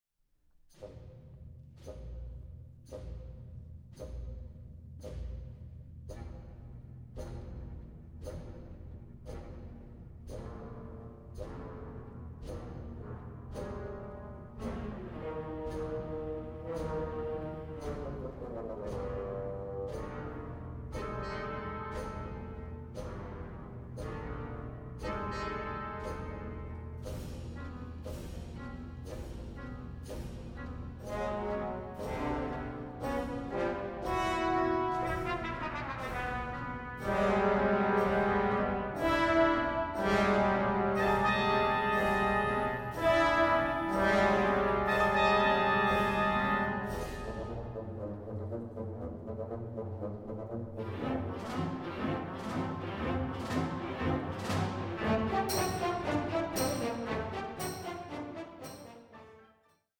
23) Orchestral interlude 2:10